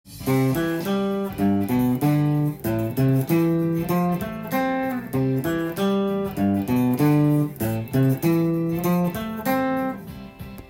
コード進行は、C/G/Am/F
コードトーン練習TAB譜
譜面通り弾いてみました
①は、コードトーンの1度、３度、５度